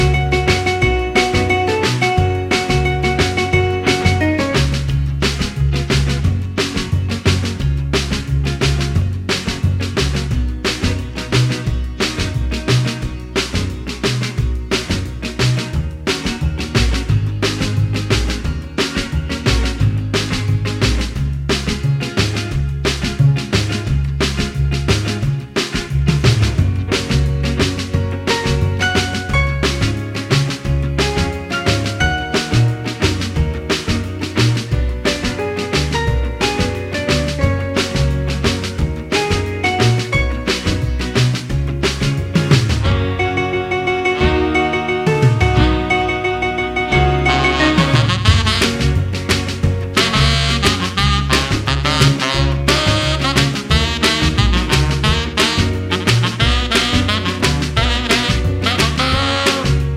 no Backing Vocals Soul / Motown 2:13 Buy £1.50